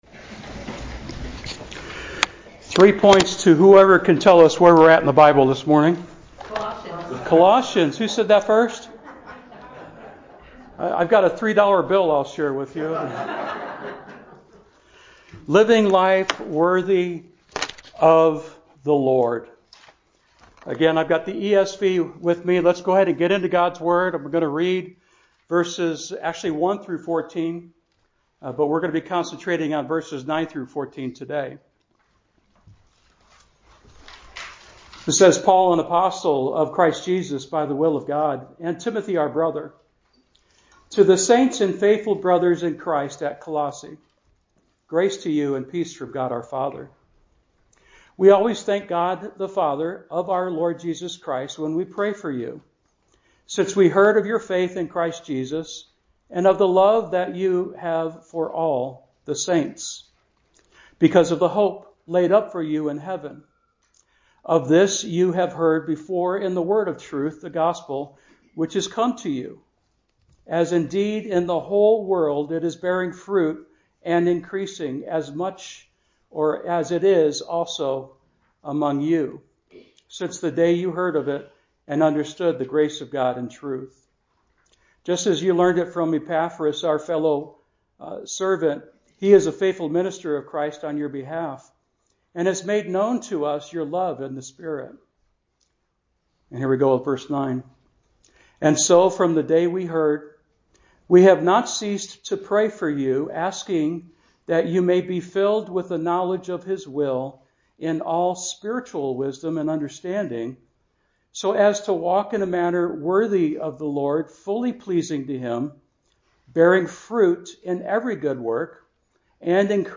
SERMON OUTLINE: To live a life worth of the Lord, we must: a. EMBRANCE God’s will (v. 9) b. BEAR spiritual fruit (v. 10) c. ENDURE in His power (v. 11) d. Give thanks for His REDEMPTION (vv. 12-14) SHARE ON Twitter Facebook Buffer LinkedIn Pin It